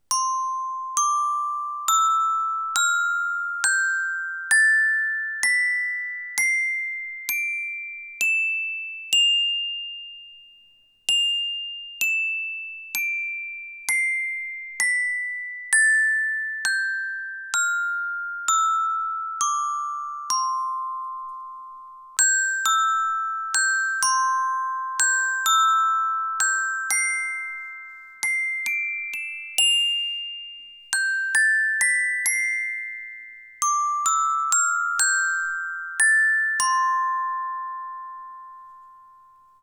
NINO Percussion C Major Scale Glockenspiel (NINO902)